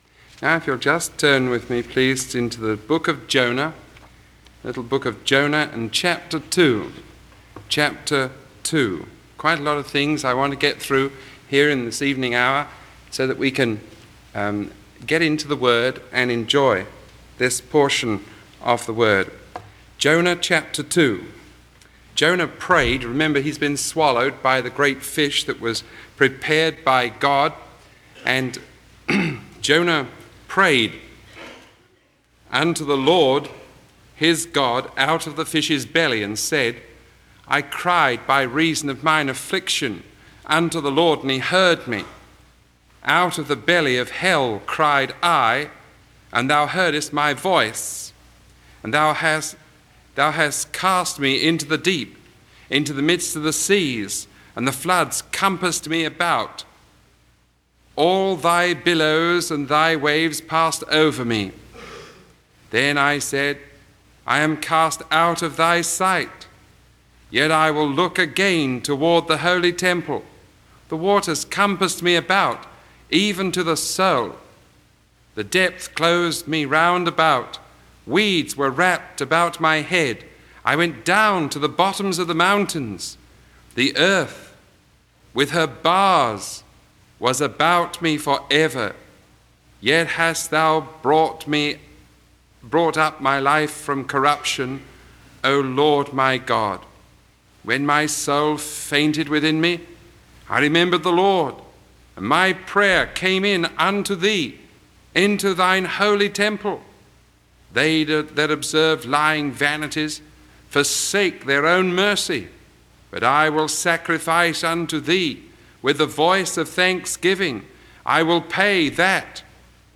Sermon 0028A recorded on March 4